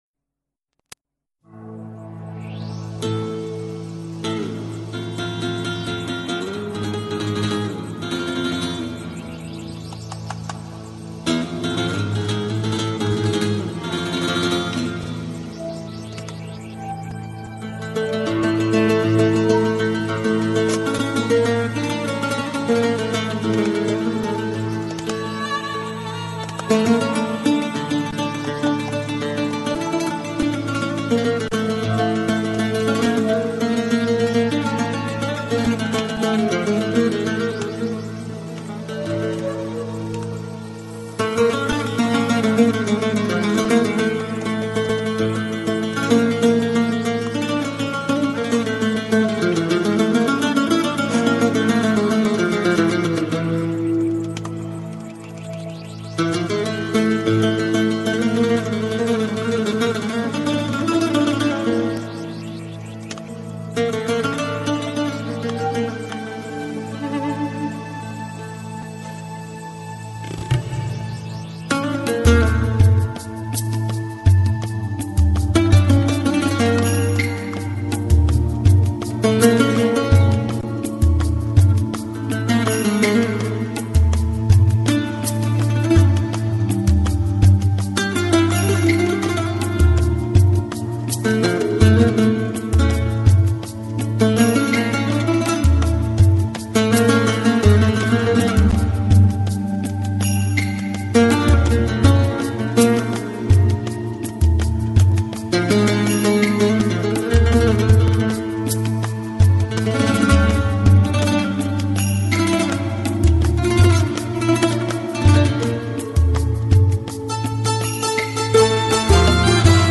Жанр: Organic House, Downtempo